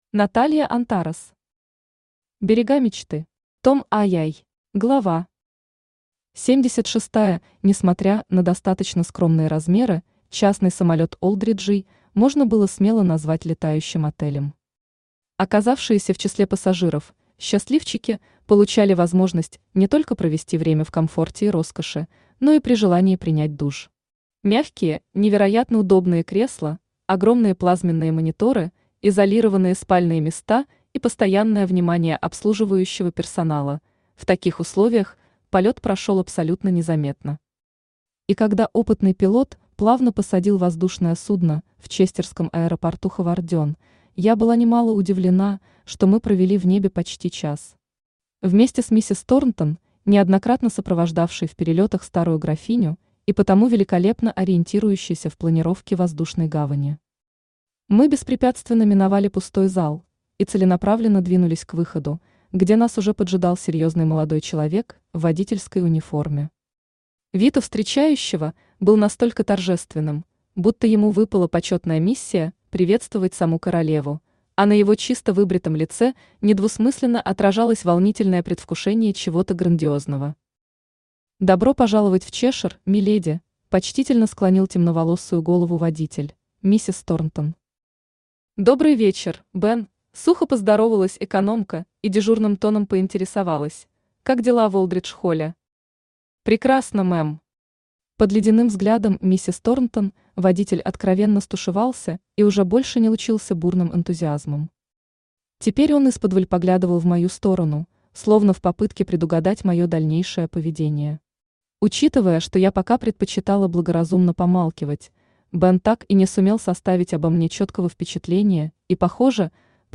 Аудиокнига Берега мечты. Том II | Библиотека аудиокниг
Том II Автор Наталья Антарес Читает аудиокнигу Авточтец ЛитРес.